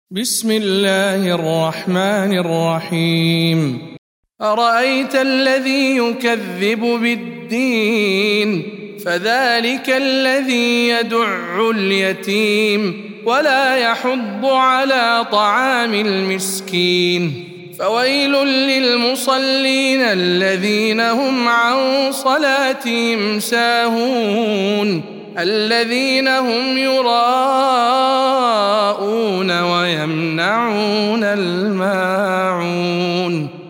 سورة الماعون - رواية إسحاق عن خلف العاشر